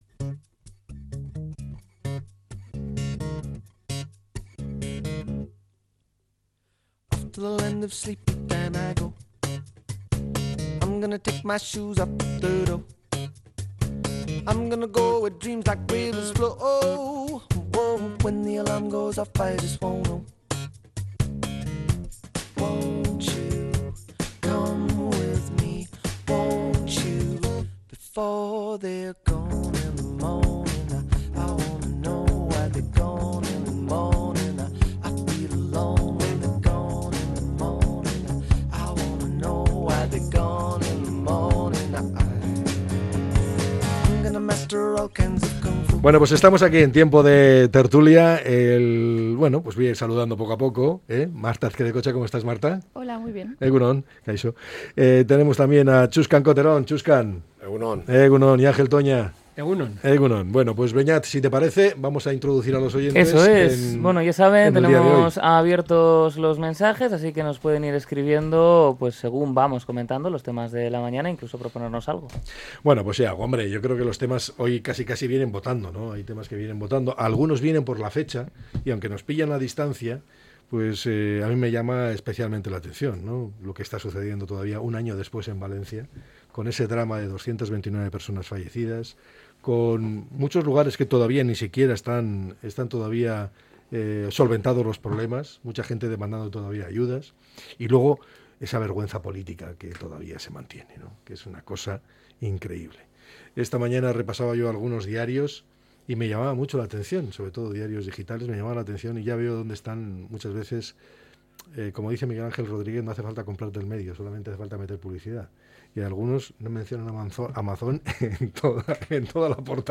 analiza a diario diferentes temas de actualidad con sus tertulianxs